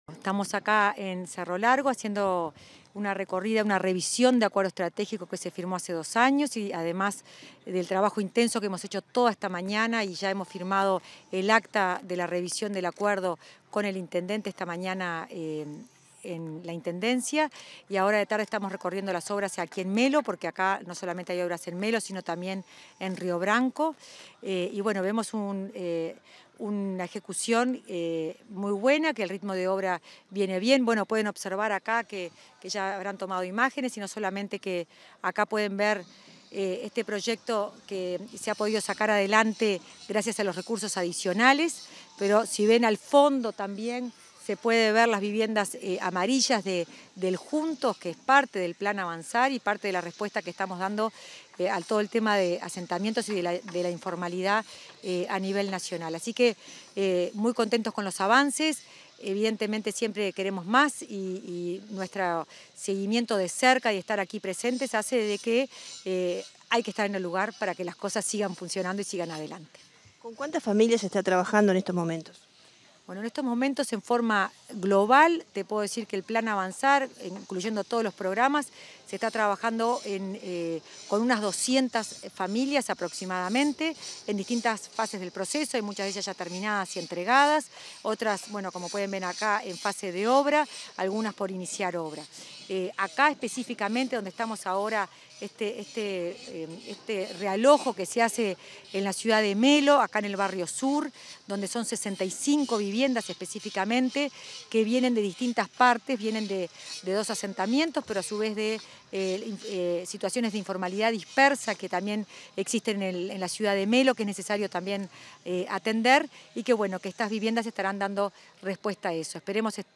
Entrevista a la directora de Integración Social y Urbana del MVOT, Florencia Arbeleche